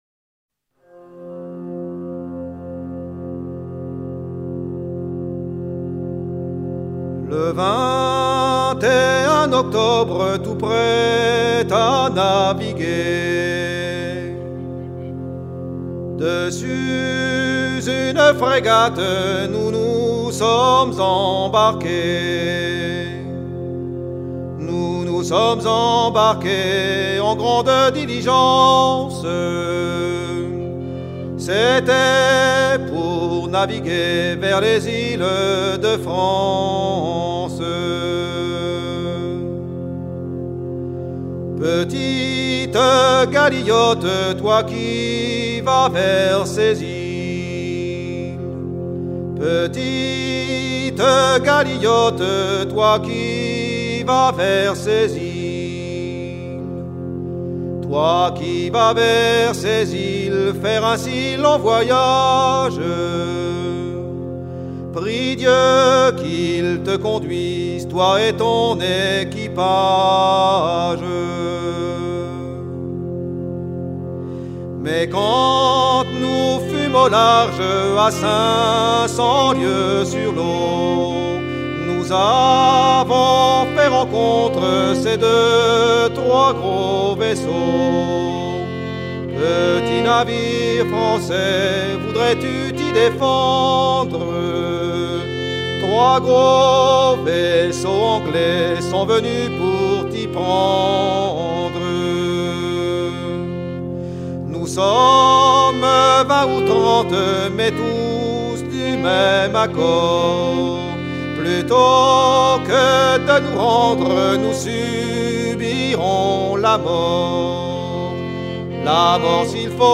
Genre strophique
Catégorie Pièce musicale éditée